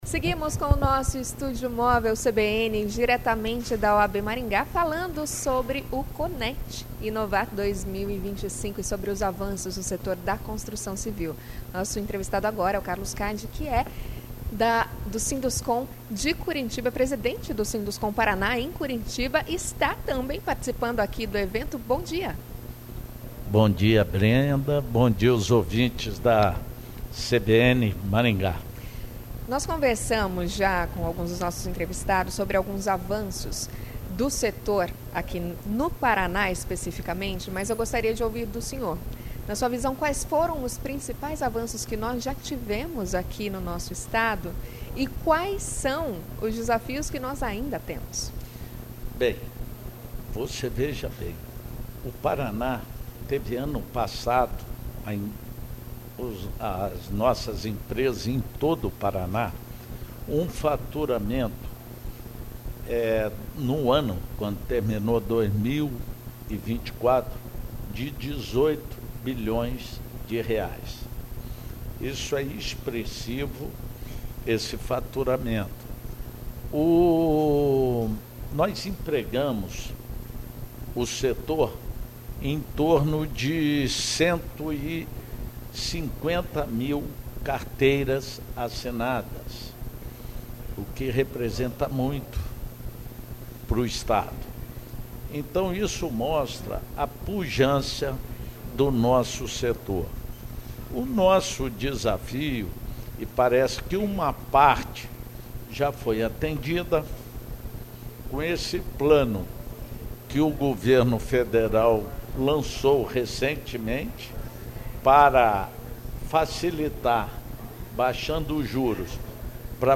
A entrevista foi realizada no Estúdio Móvel da CBN, instalado na sede da OAB Maringá, de onde ocorre a edição do Conecti, que antecede a cerimônia de entrega do Prêmio Sinduscon 2025.